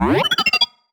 sci-fi_driod_robot_emote_19.wav